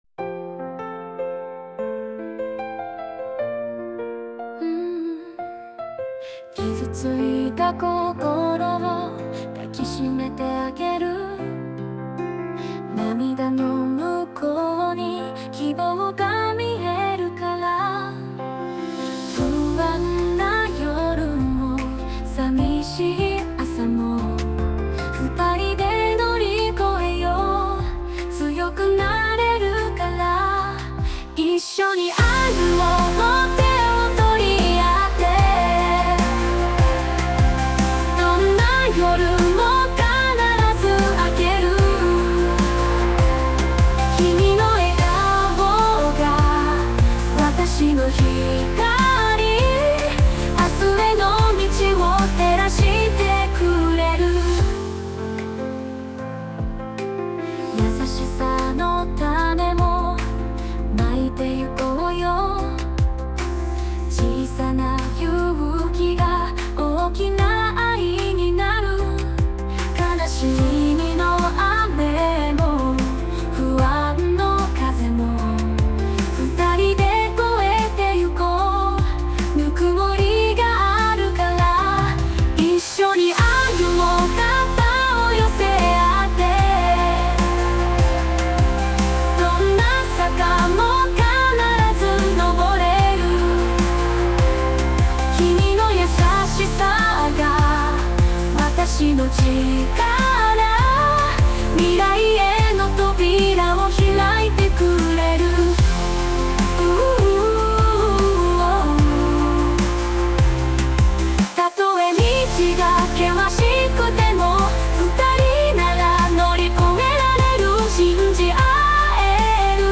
著作権フリーBGMです。
女性ボーカル（邦楽・日本語）曲です。
ベタに、愛と希望をテーマにした前向きなバラード曲として制作しました！